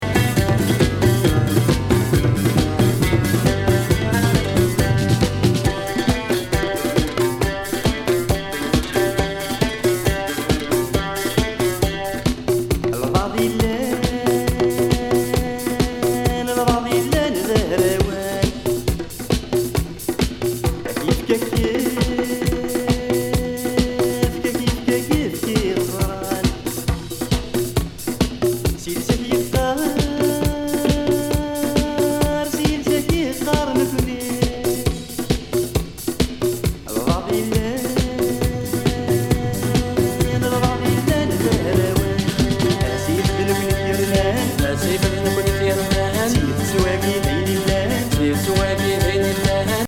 疾走中近東サイケ・フォーキー